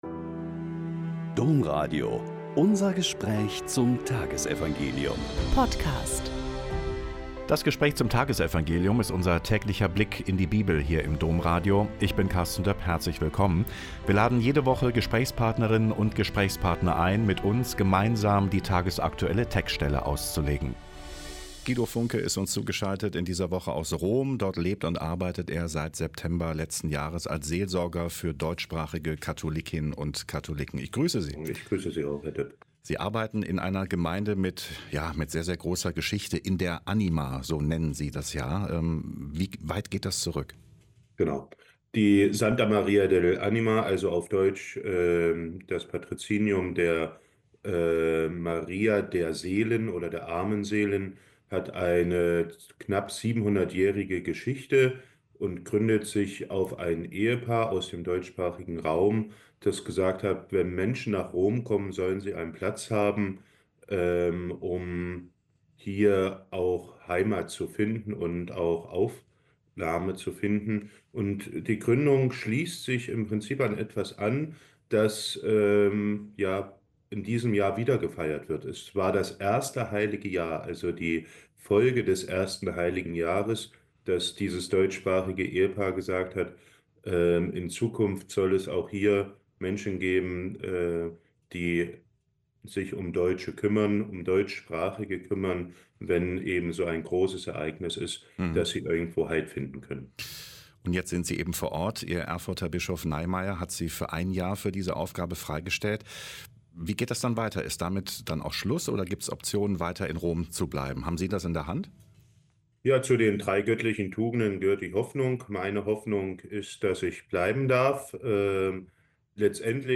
Mk 6,1b-6 - Gespräch